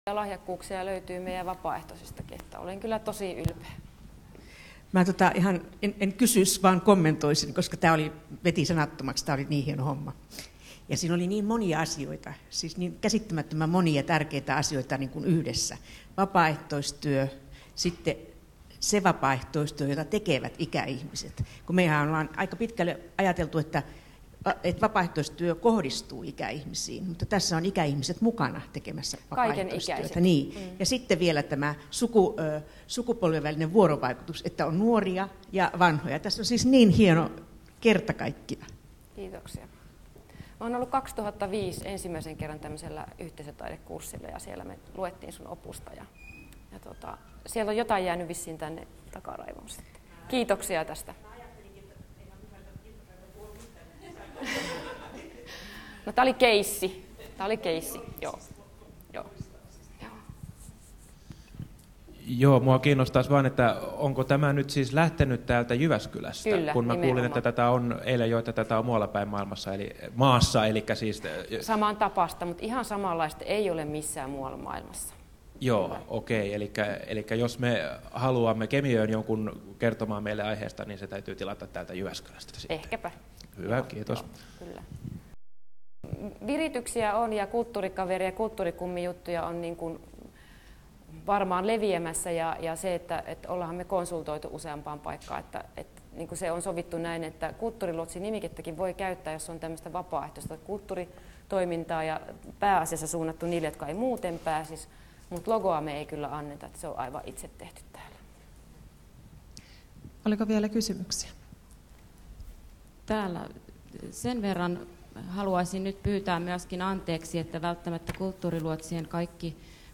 Keskustelu